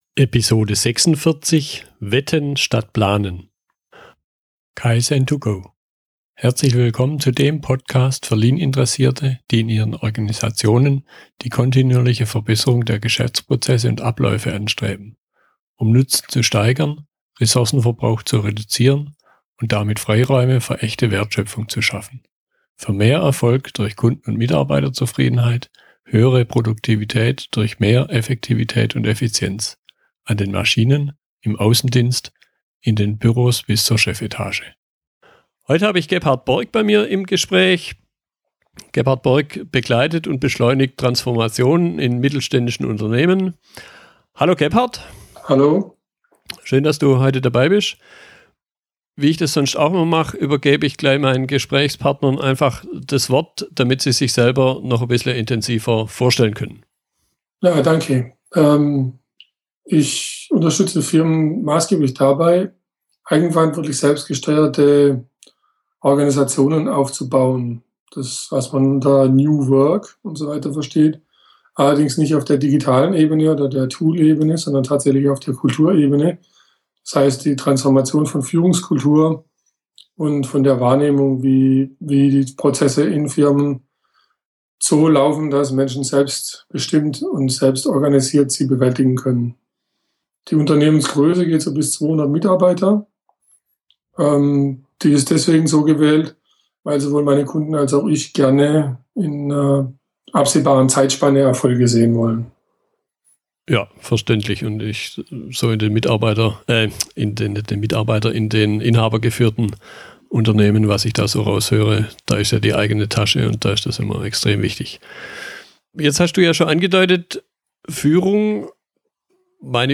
Wir unterhalten uns über eine Alternative zum "normalen" Planungsprozess im Umgang mit der unbekannten und unsicheren Zukunft – Wetten statt Planen